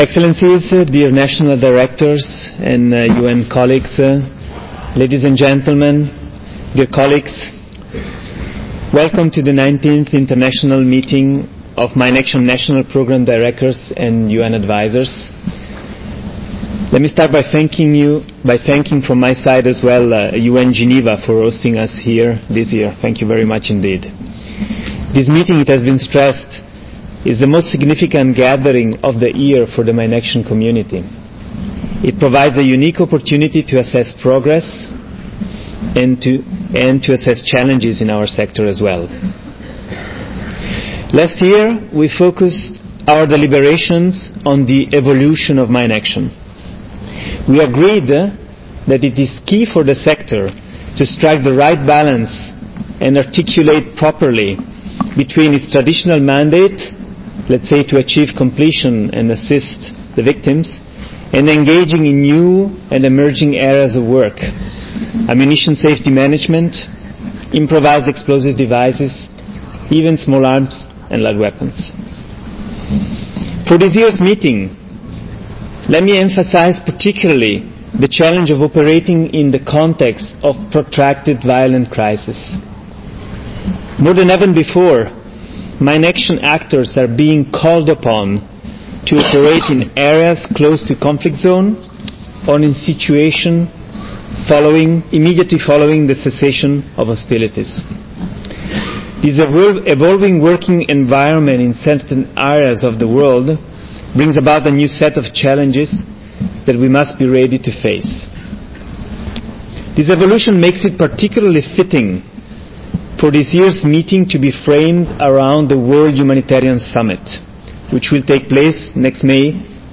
opening ceremony